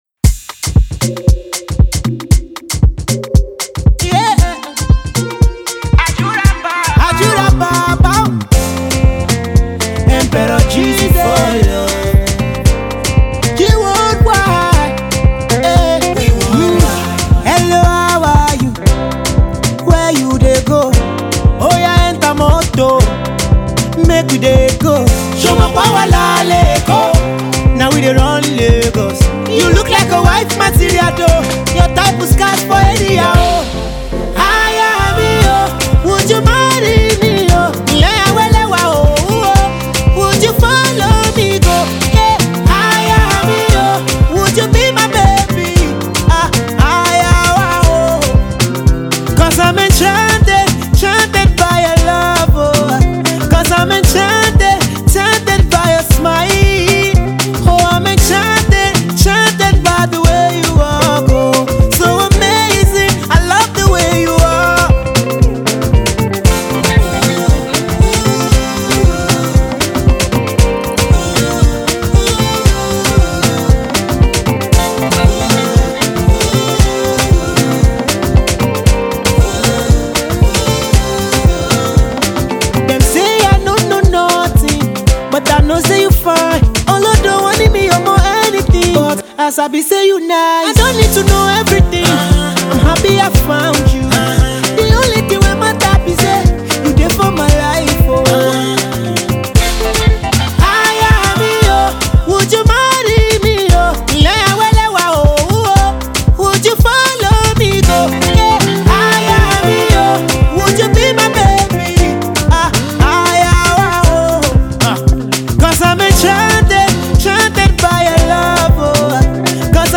talented singer
showcases his pop sensibilities